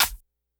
Perc_121.wav